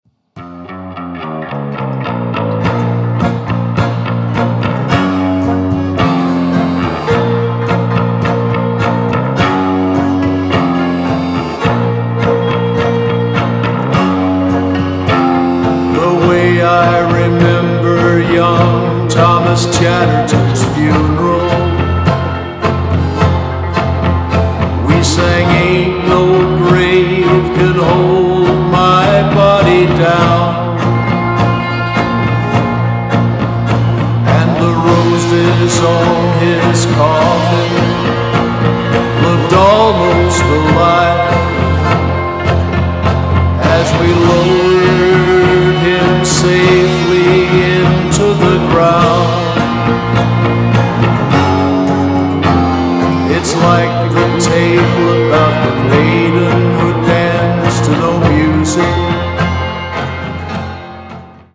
• Americana
• Country
• Folk
• Singer/songwriter